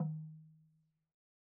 LogDrumHi_MedM_v1_rr1_Sum.wav